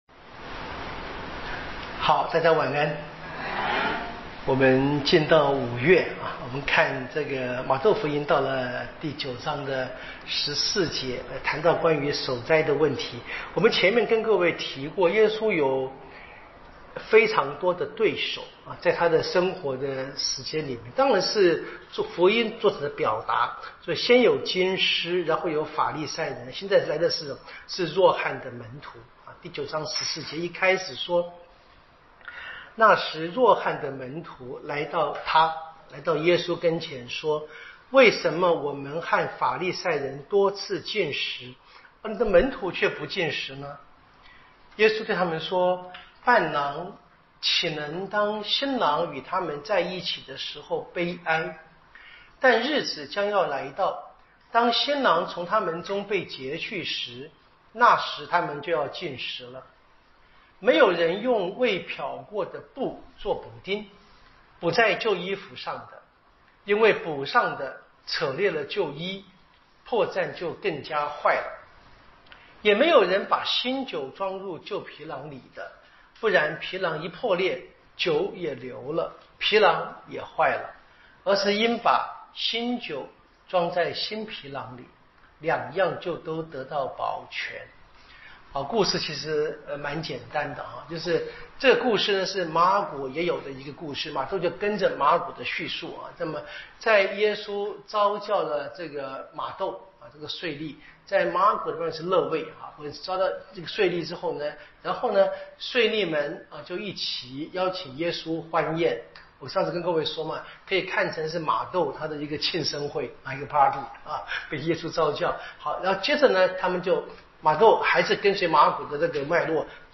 圣经讲座】《玛窦福音》